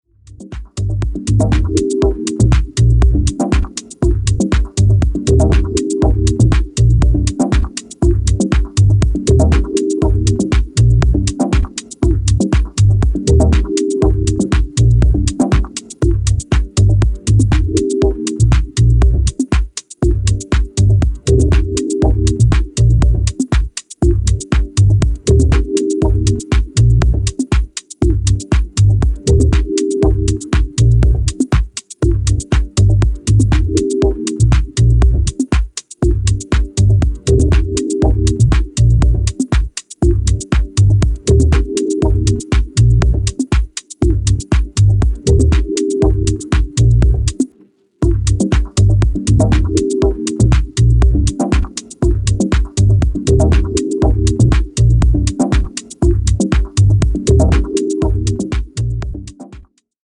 Deep House Dub Techno House